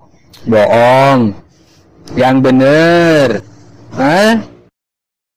Kategori: Suara viral
Keterangan: Suara Bohong, Yang Benar Meme adalah tren edit video di media sosial Indonesia, menggunakan efek suara untuk menambahkan unsur humor dan keabsuran, membuat video lebih menarik dan sering viral.